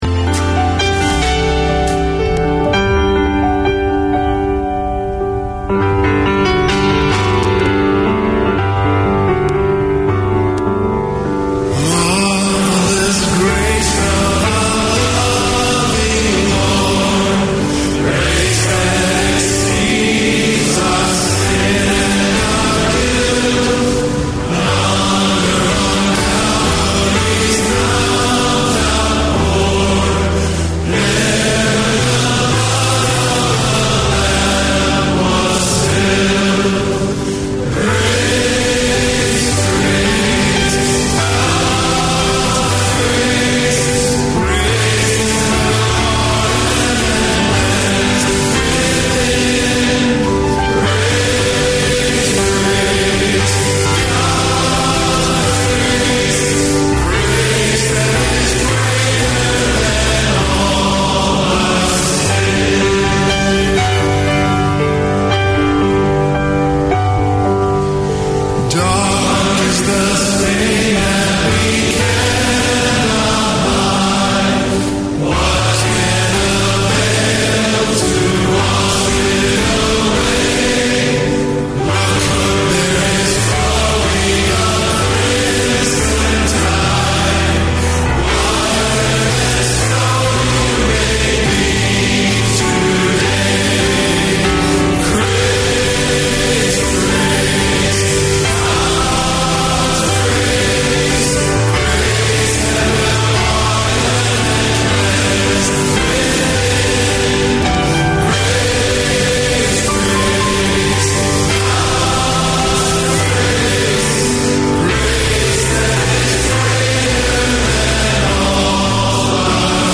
Community Access Radio in your language - available for download five minutes after broadcast.
Pasifika Wire Live is a talanoa/chat show featuring people and topics of interest to Pasifika and the wider community.